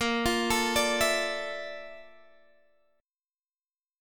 A#M#11 chord